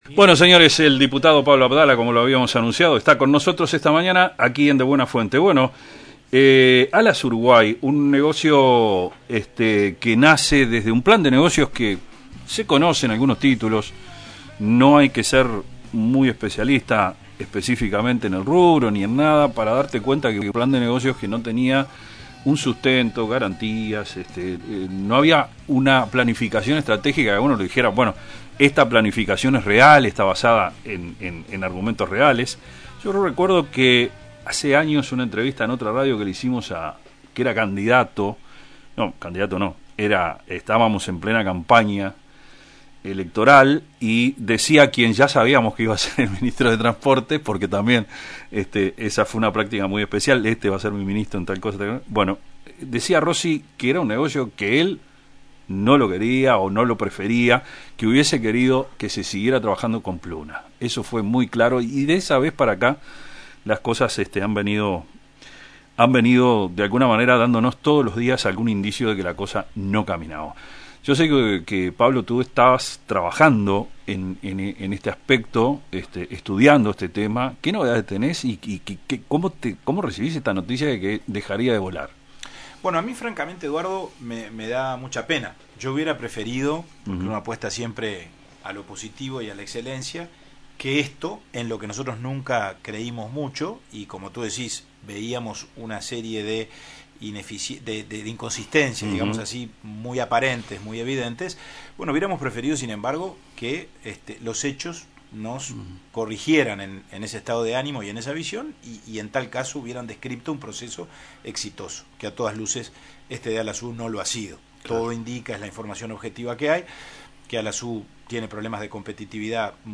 El diputado del Partido Nacional Pablo Abdala, sostuvo en De Buena Fuente que “Alas U fue el producto de un capricho del entonces presidente José Mujica”.